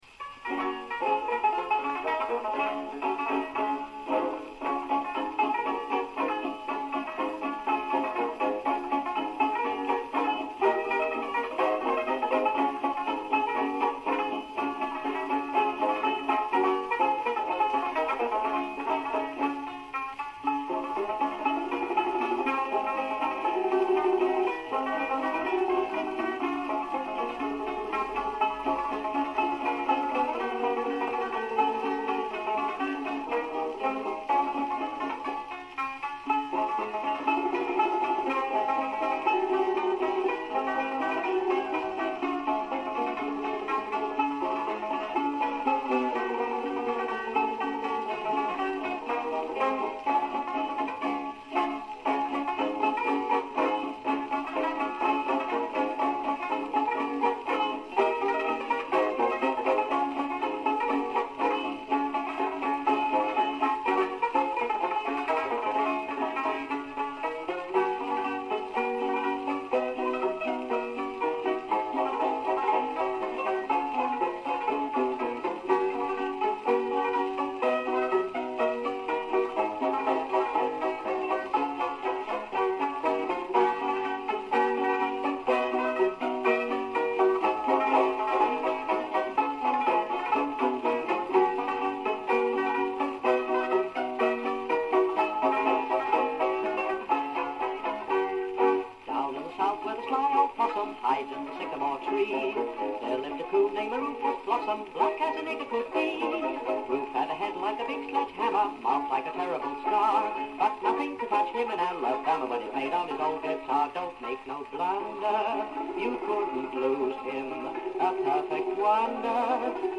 on tenor banjo.